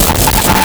Player_Glitch [27].wav